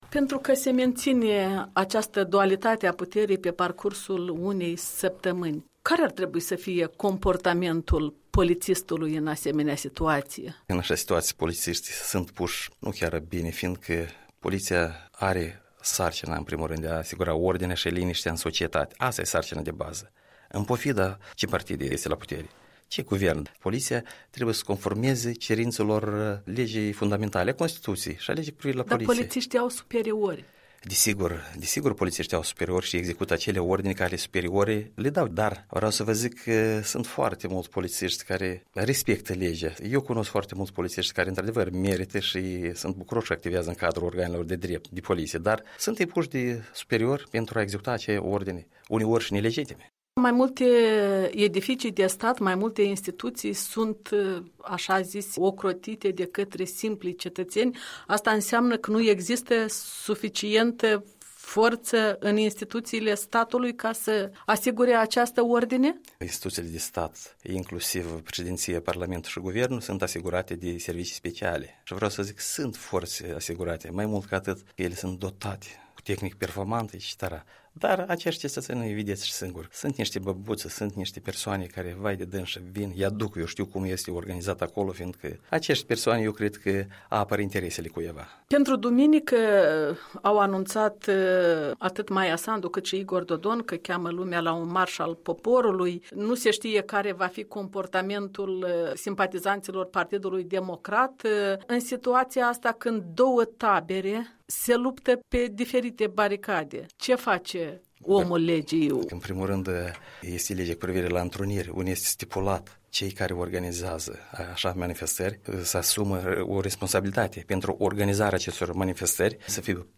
Interviu cu cu un colonel (în rezervă) de poliție.